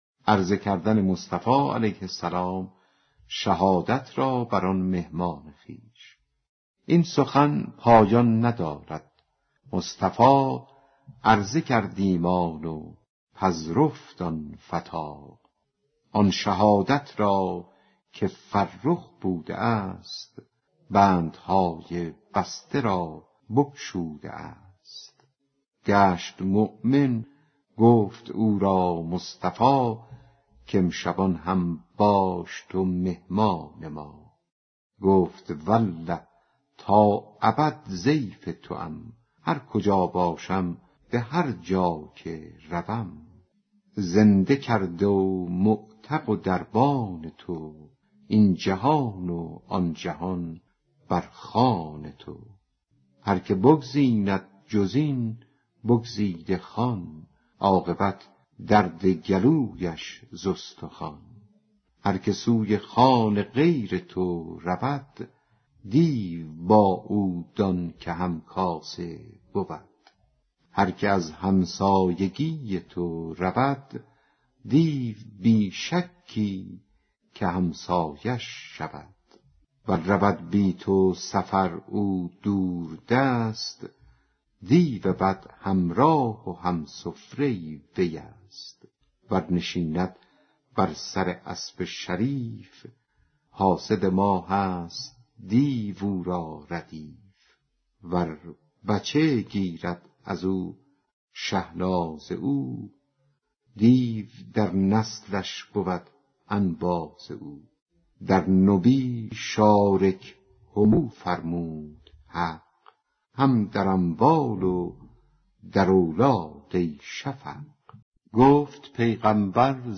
دکلمه عرضه کردن مصطفی شهادت را بر آن مهمانِ خویش